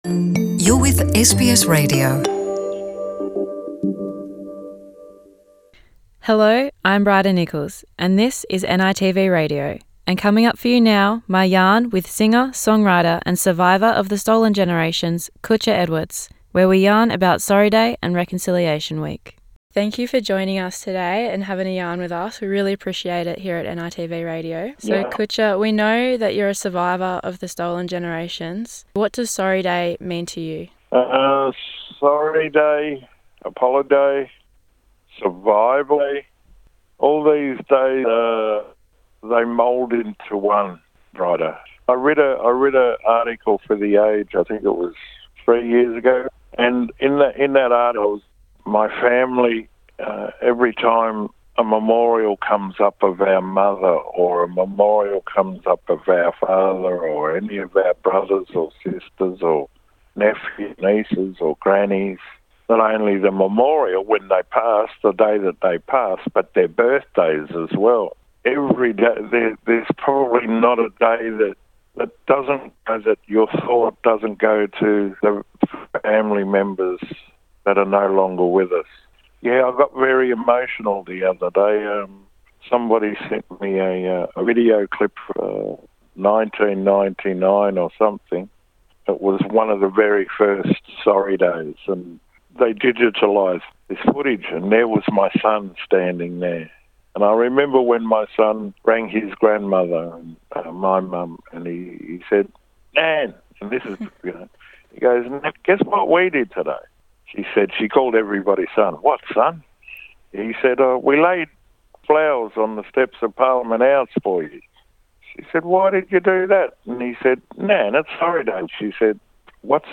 To acknowledge Sorry Day and Reconciliation week 2021, NITV Radio had a yarn with Kutcha Edwards. A proud Mutti Mutti man, singer, songwriter, activist and stolen generations survivor.